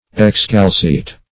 Search Result for " excalceate" : The Collaborative International Dictionary of English v.0.48: Excalceate \Ex*cal"ce*ate\, v. t. [L. excalceatus, p. p. of excalceare to unshoe.